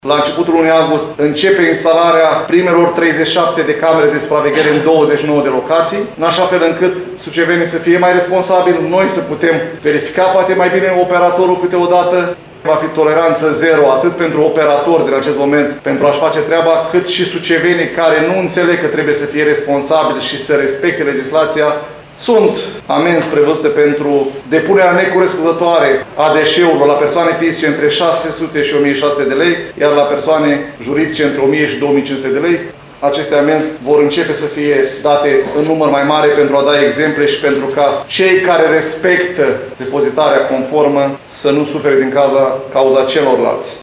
Viceprimarul LUCIAN HARȘOVSCHI a declarat că – dacă până acum capacitatea maximă zilnică de colectare era de 1.200 metri cubi – noile pubele permit colectarea a 2 mii metri cubi.